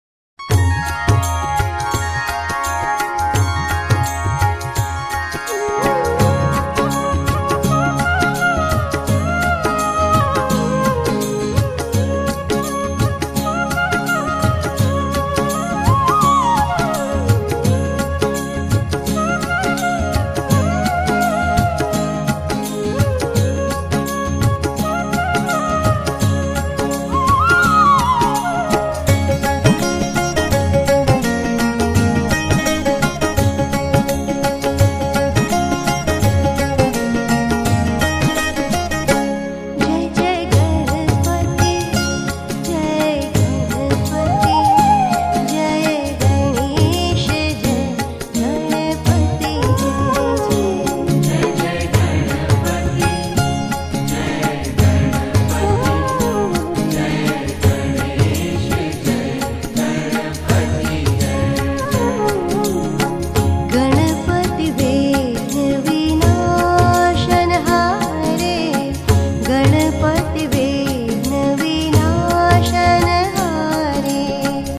Bhakti Sangeet